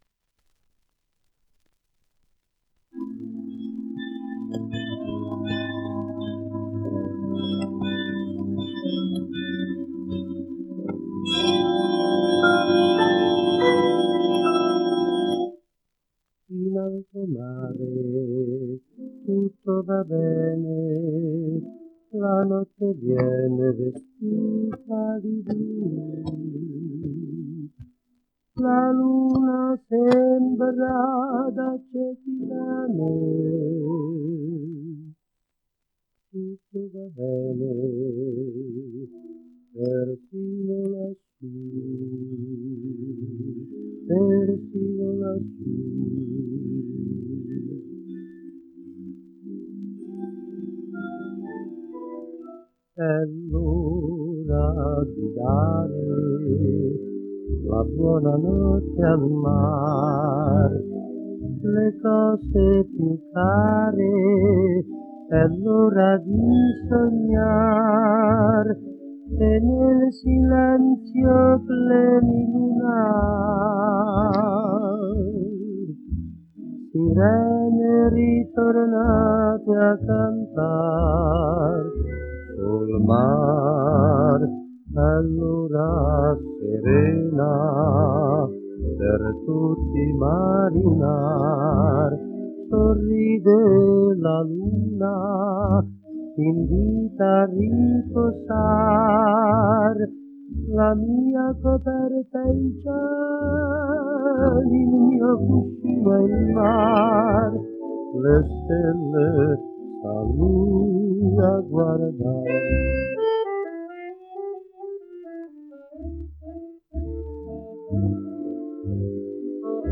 1 disco : 78 rpm ; 25 cm Intérprete